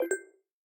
ChargerPluggedIn.ogg